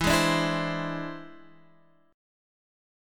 E+7 chord {x 7 6 7 5 8} chord